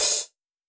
Chart Open Hat 01.wav